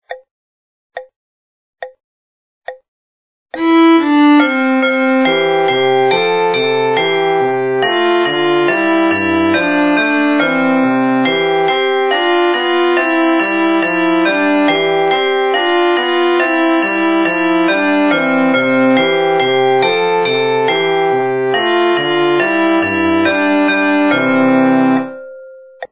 The tune for "Ah vous dirais.." is much the same as "Twinle twinkle little star".